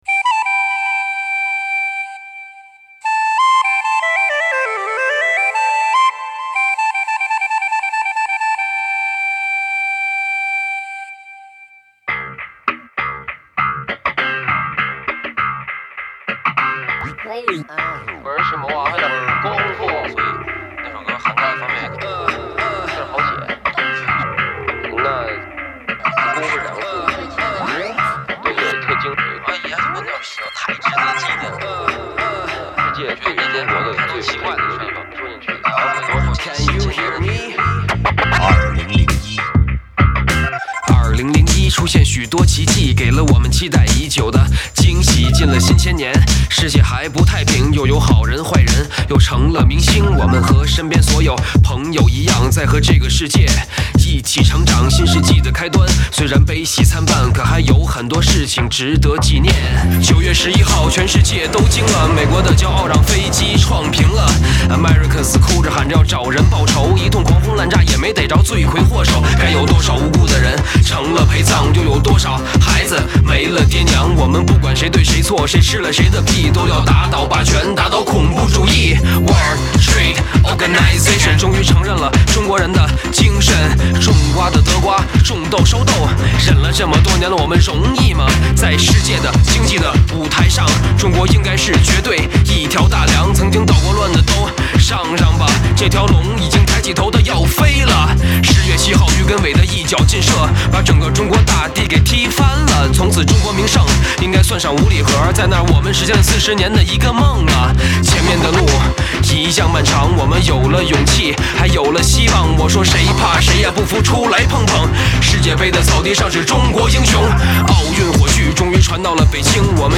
“冲劲十足，动感无限”